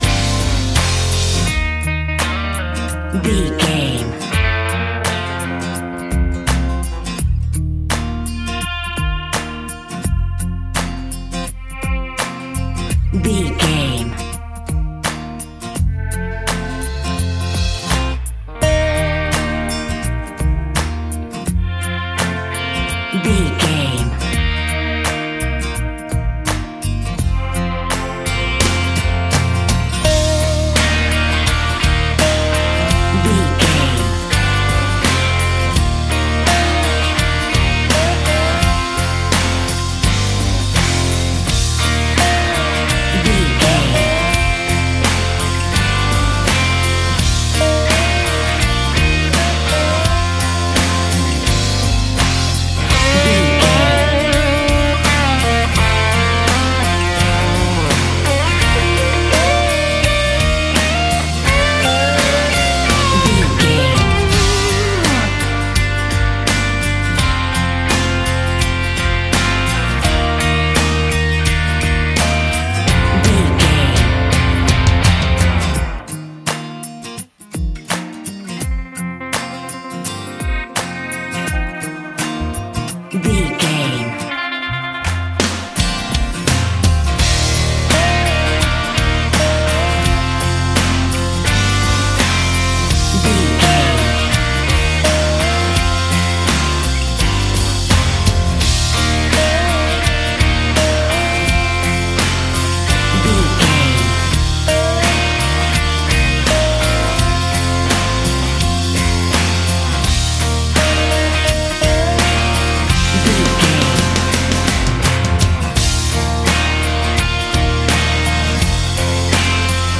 Ionian/Major
E♭
acoustic guitar
electric guitar
drums
bass guitar